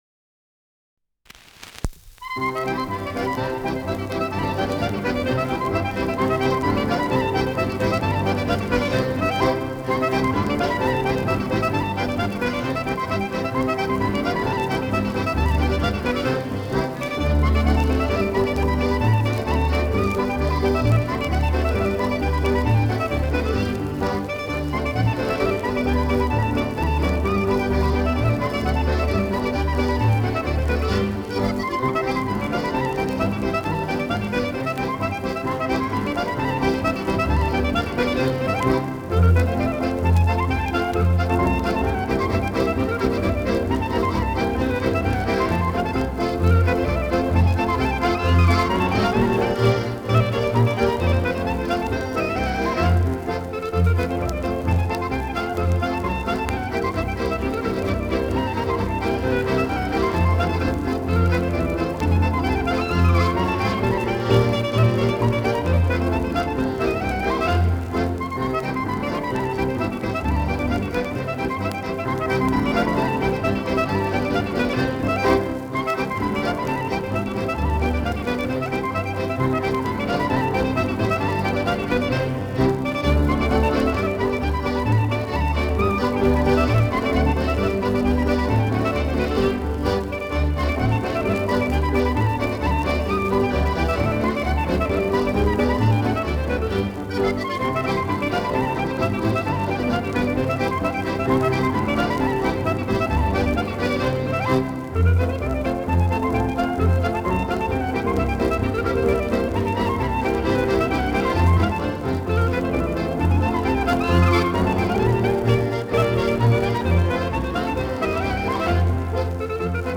Schellackplatte
Tonrille: Kratzer 1 Uhr Leicht
Ländlerkapelle* FVS-00018